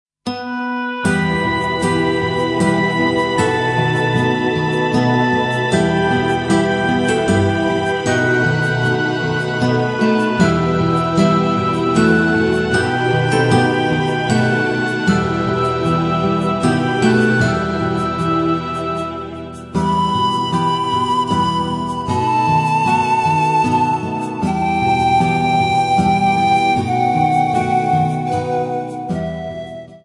A compilation of Christmas Carols from Poland
panpipes